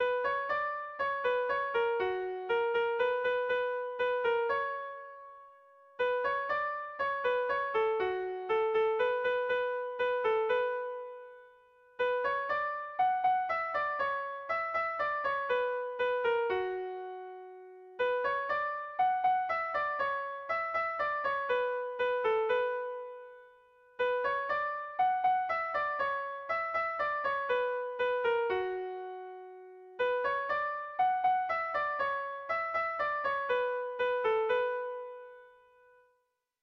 A1A2B1B2